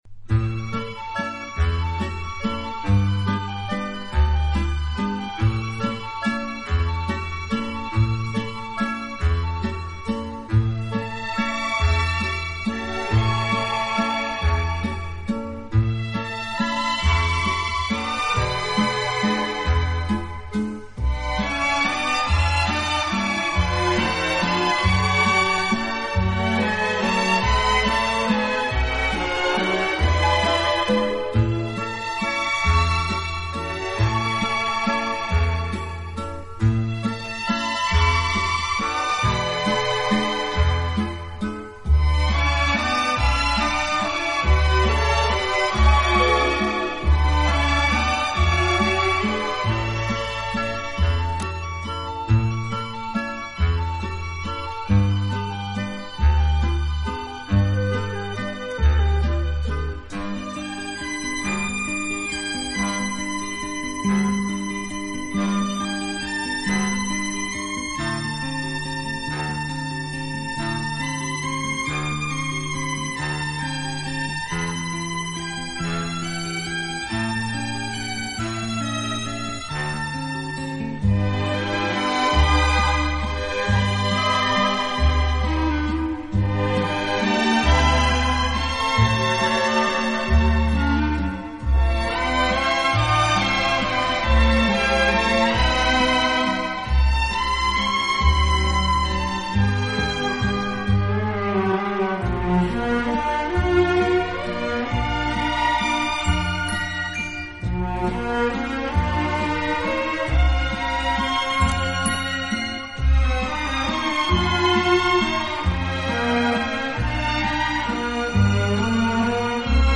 【轻音乐】
音乐类型: Instrumental / Easy Listening